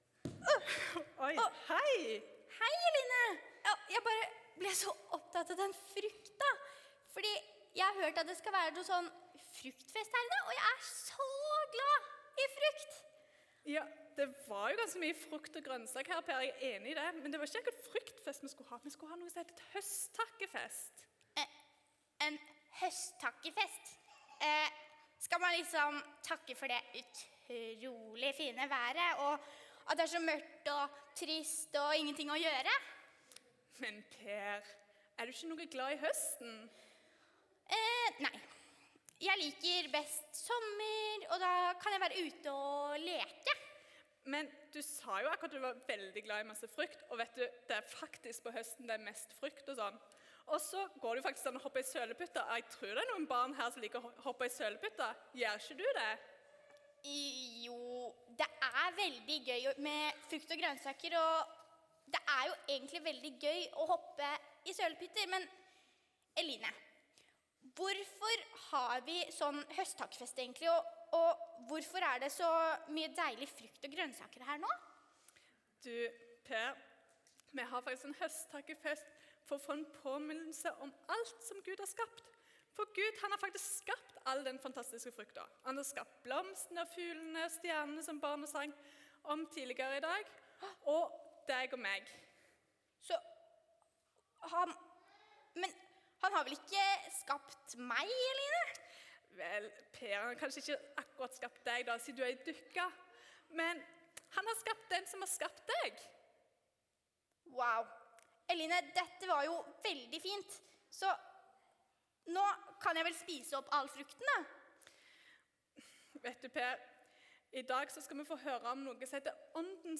Andakt